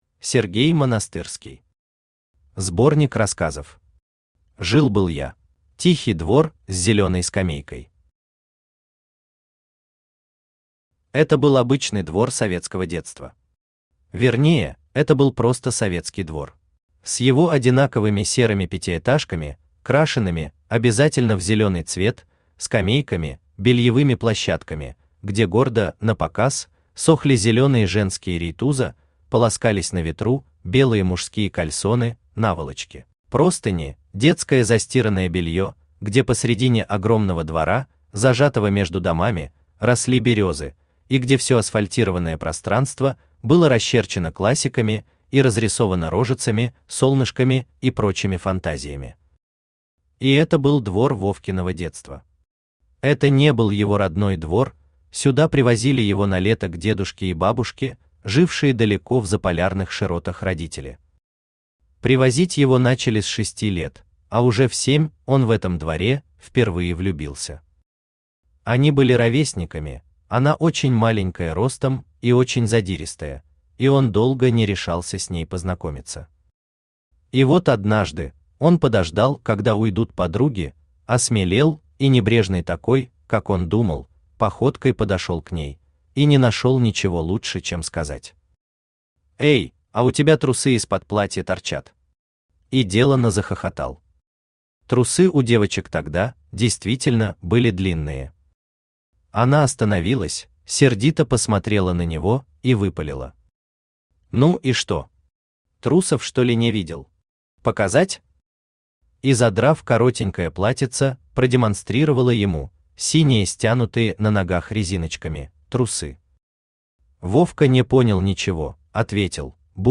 Аудиокнига Сборник рассказов. Жил-был я | Библиотека аудиокниг
Жил-был я Автор Сергей Семенович Монастырский Читает аудиокнигу Авточтец ЛитРес.